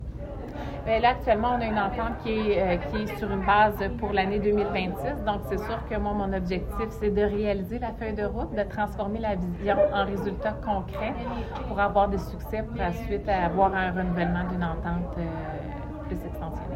La MRC de La Haute‑Yamaska a annoncé mardi, lors d’une conférence de presse, la conclusion d’une nouvelle entente d’une durée d’un an en matière de développement touristique avec Commerce Tourisme Granby région (CTGR).